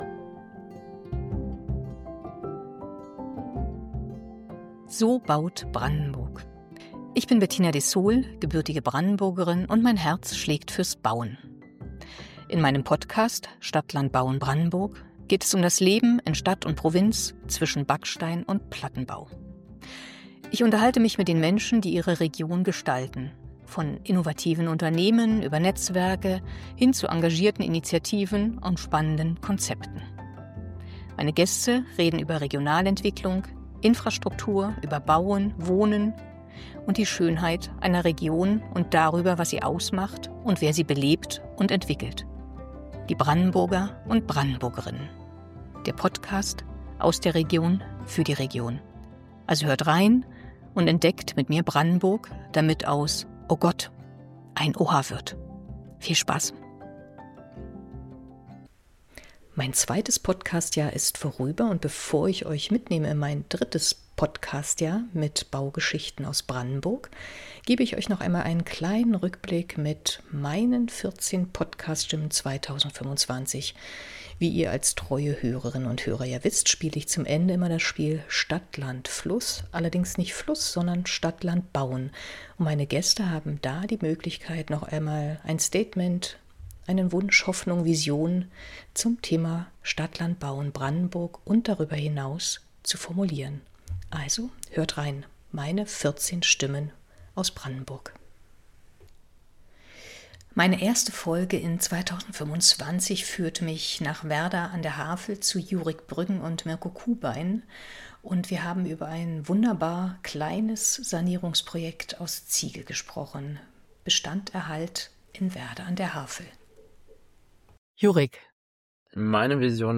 Das waren meine 14 Stimmen aus Brandenburg und darüber hinaus in meinem zweiten Podcast-Jahr.